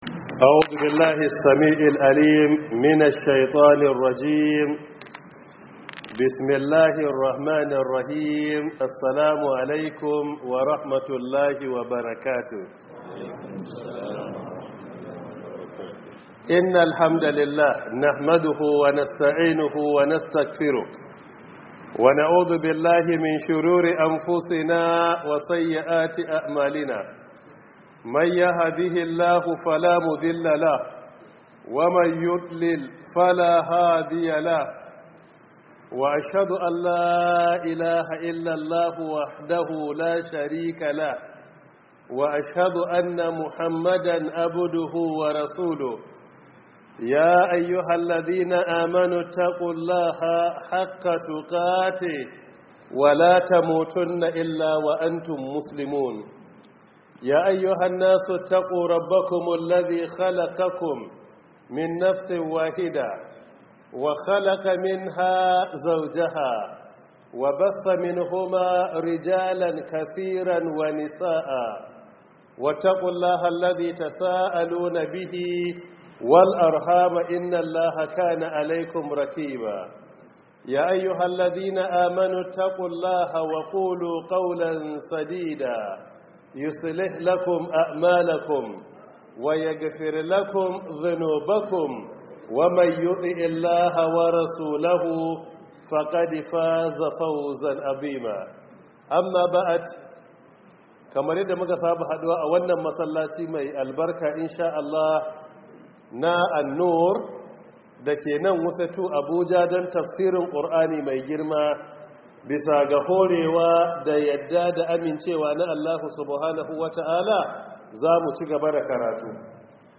018 Prof Isa Ali Pantami Tafsir 2026